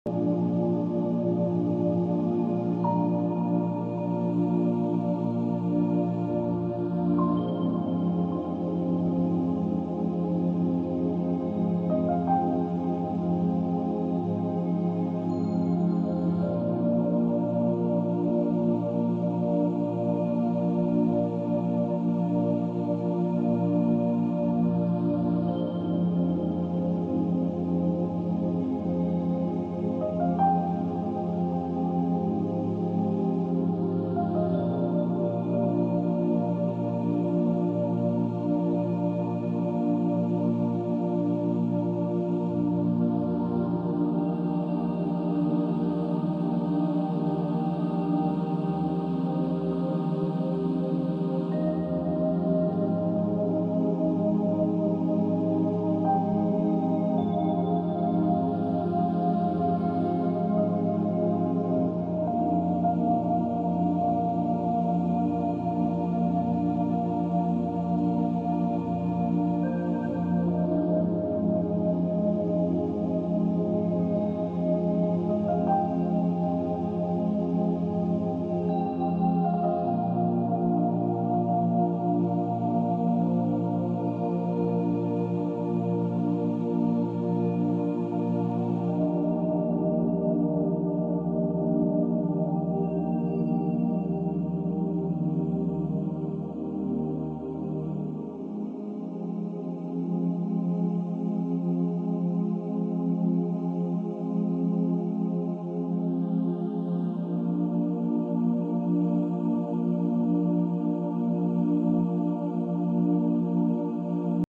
174 Hz | Fréquence du sound effects free download